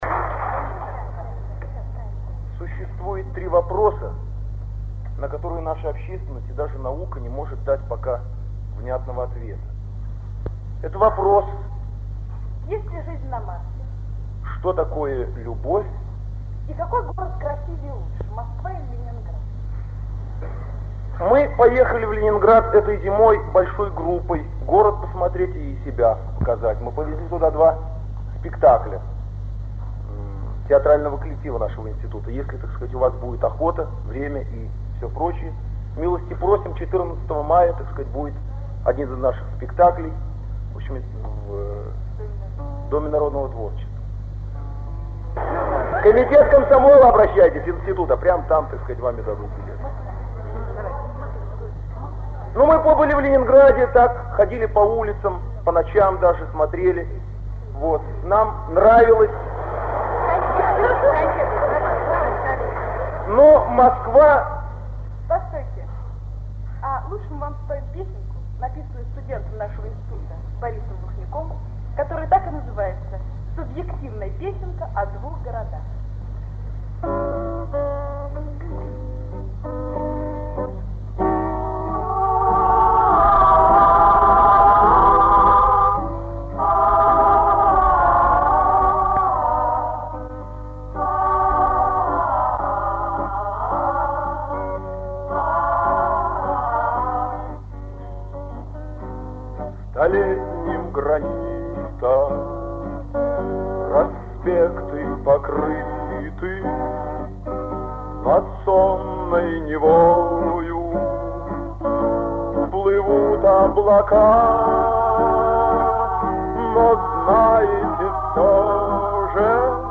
ФРАГМЕНТЫ КОНЦЕРТА-КОНКУРСА 27 АПРЕЛЯ 1959 Г. ДК МЭИ
3. Борис Вахнюк и женский ансамбль